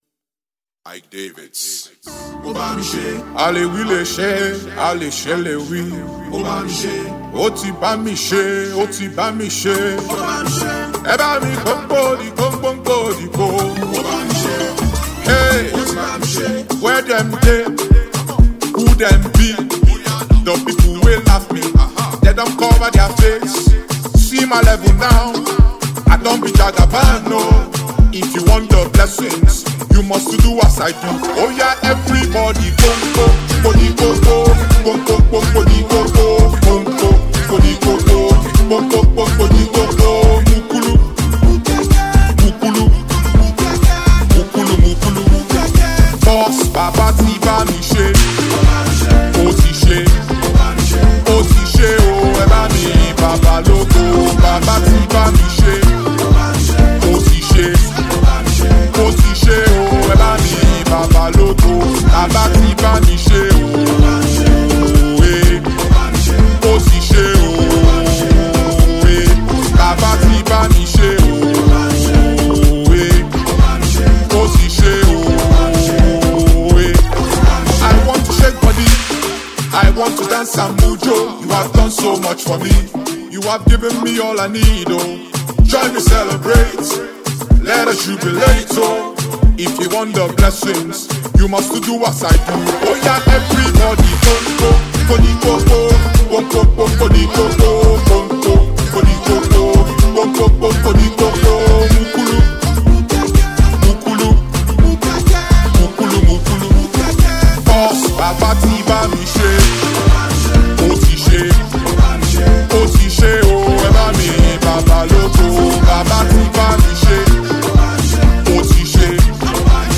Afro-Pop
uplifting groovy vibes
The energetic singer